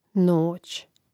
nȏć noć im. ž. (GD nȍći, A nȏć, L nòći, I nȍću/nȍći; mn. NA nȍći, G nòćī, DLI nòćima)